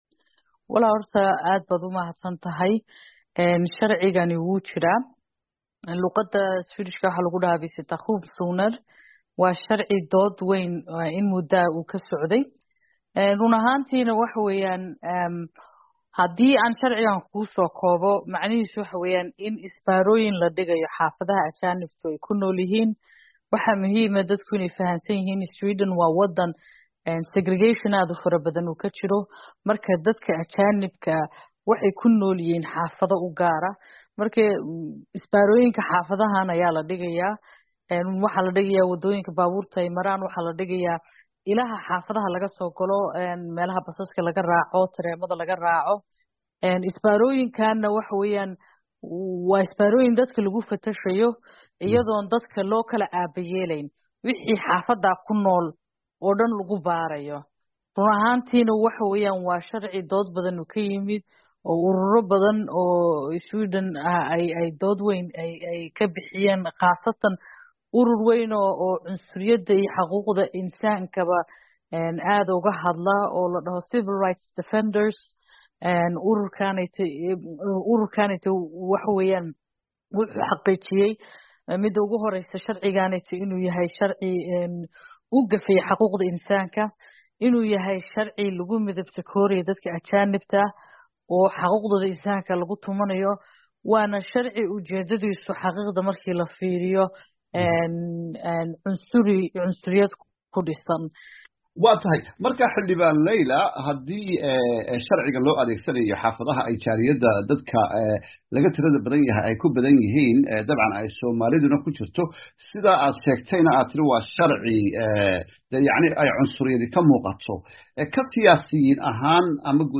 ka wareystay xildhibaan Laila Ali Elmi oo xubin ka ah baarlamanka dalka Sweden